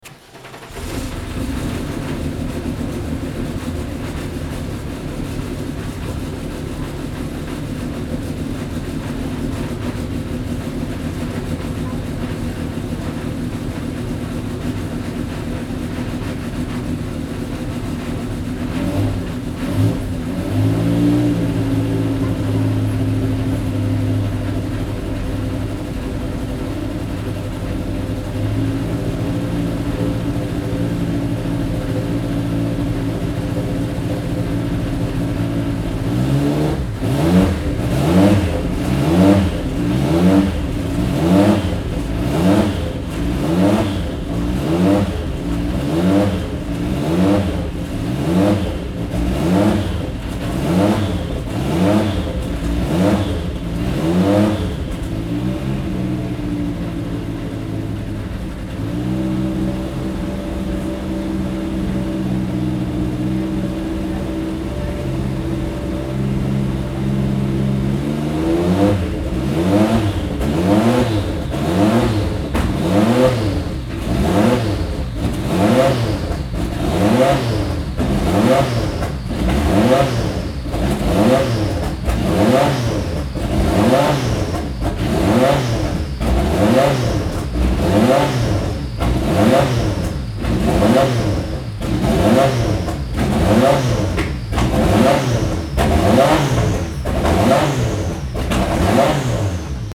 Porsche Sound Night 2017 - a loud night at the museum (Event Articles)